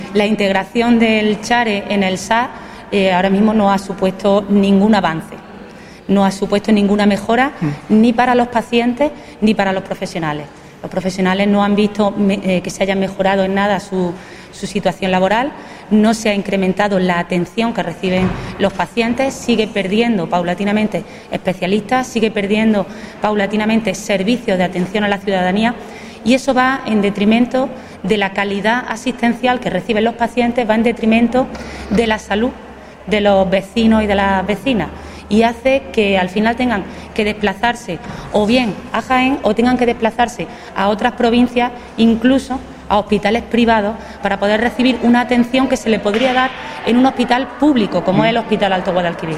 La parlamentaria socialista Mercedes Gámez advirtió hoy que la integración del Hospital de Andújar en el SAS “no ha supuesto ningún avance ni mejora ni para los pacientes ni para los profesionales”. Gámez hizo estas declaraciones antes de mantener una reunión con colectivos sanitarios en Andújar, dentro del proceso de escucha activa abierto por el PSOE-A.